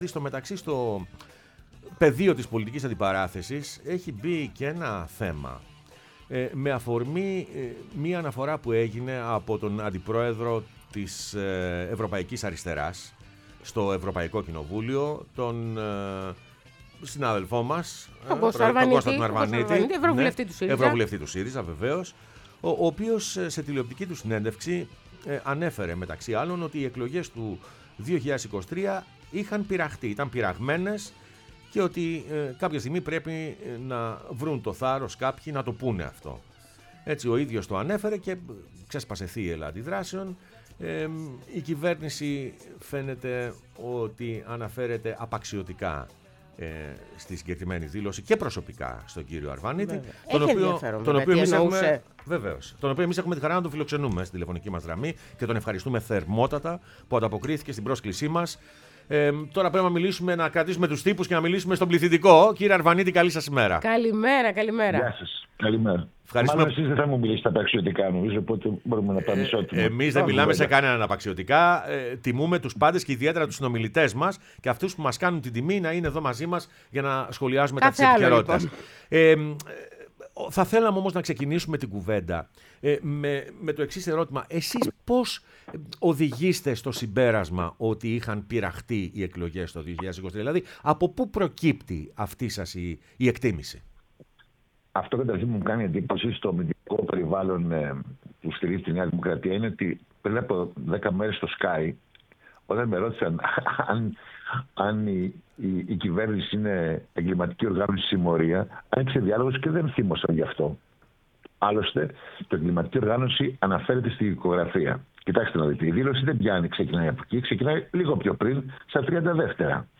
Ο Κώστας Αρβανίτης, αντιπρόεδρος Αριστεράς Ευρωπαϊκού Κοινοβουλίου – Ευρωβουλευτης ΣΥΡΙΖΑ, μίλησε στην εκπομπή «Πρωινές Διαδρομές»